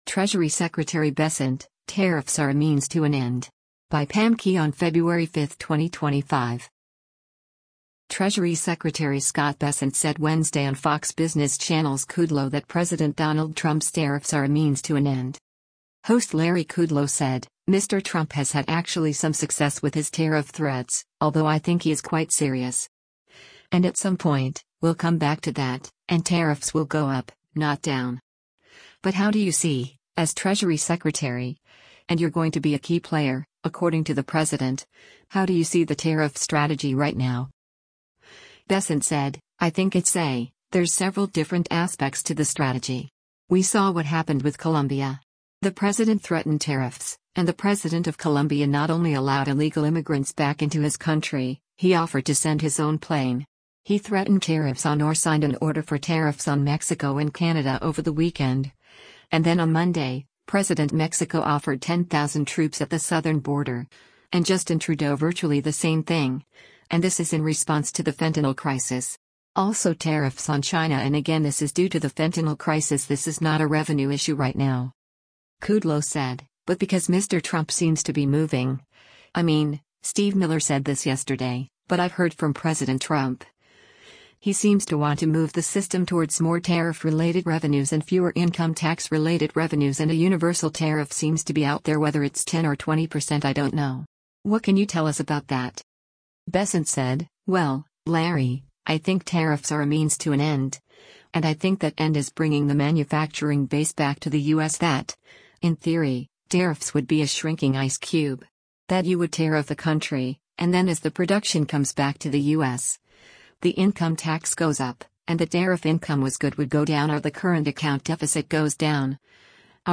Treasury Secretary Scott Bessent said Wednesday on Fox Business Channel’s “Kudlow” that President Donald Trump’s tariffs are a “means to an end.”